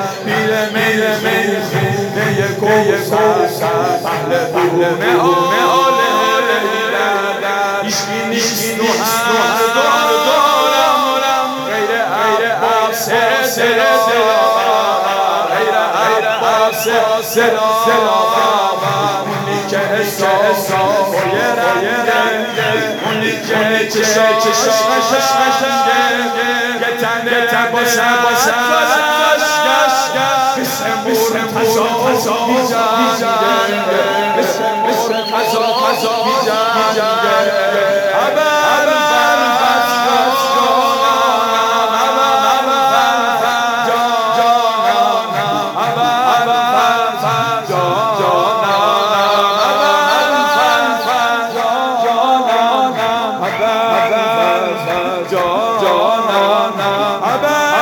جشن ولادت حضرت عباس(ع)